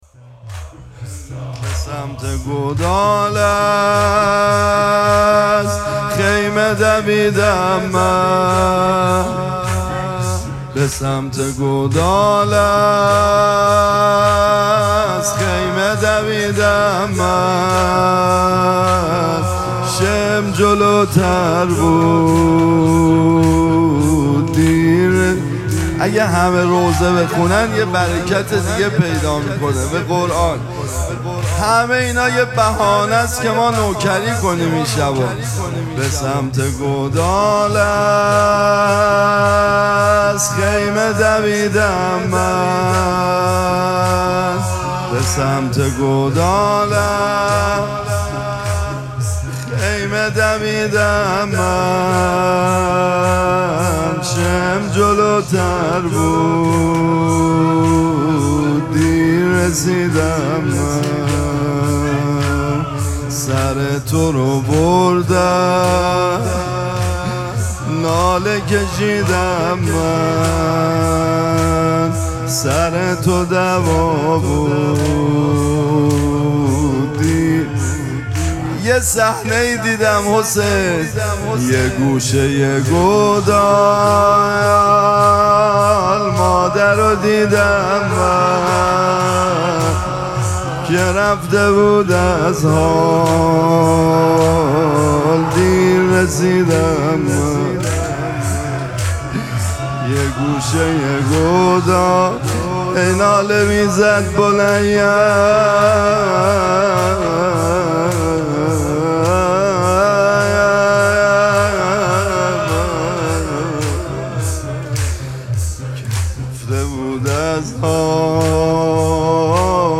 مراسم مناجات شب هفدهم ماه مبارک رمضان
مداح